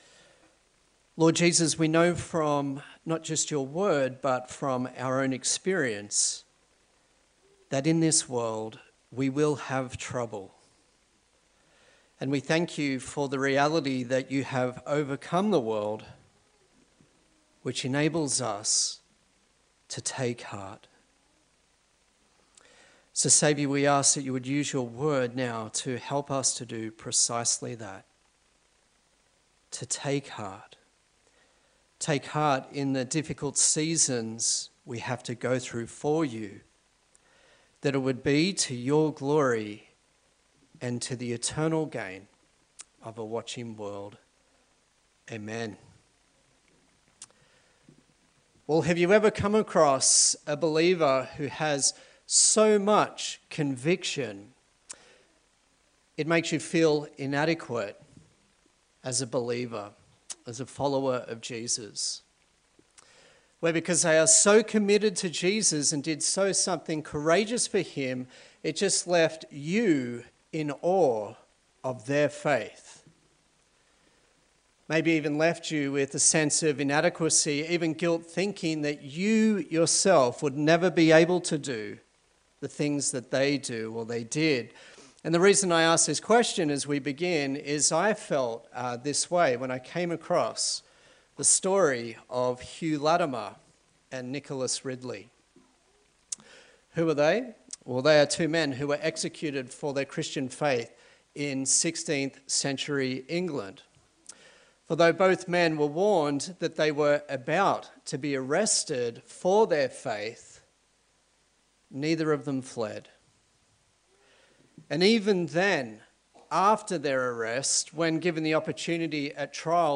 A sermon in the series on the book of Daniel
Daniel Passage: Daniel 3 Service Type: Sunday Service